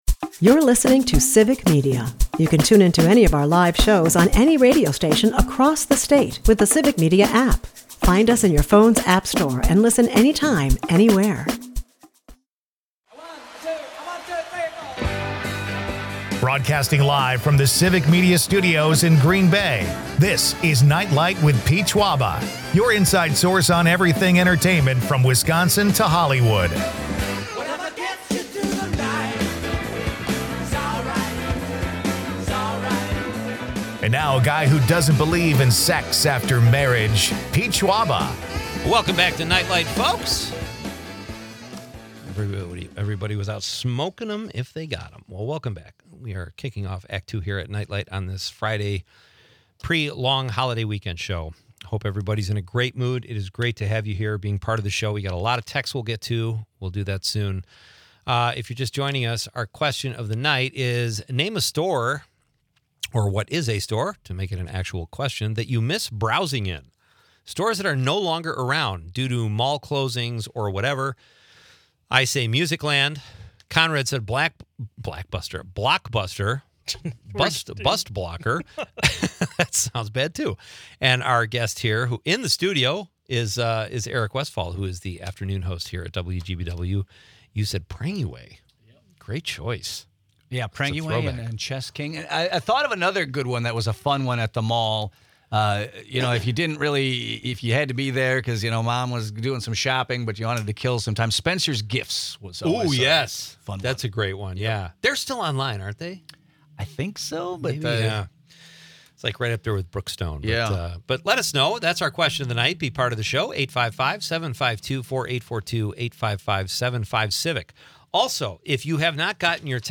Broadcasting from Civic Media Studios in Green Bay